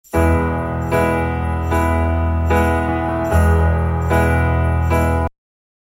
Nur ein paar Akkorde vom Anfang…